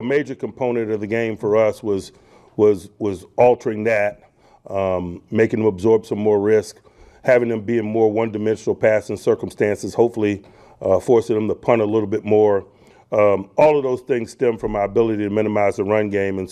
Steelers Head Coach Mike Tomlin continued to heap praise on his defensive unit during his weekly news conference on Tuesday.